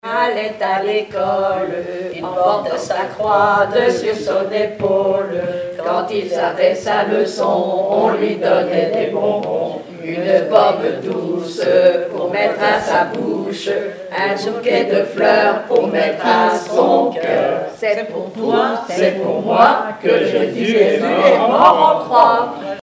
enfantine : prière, cantique
Chansons, formulettes enfantines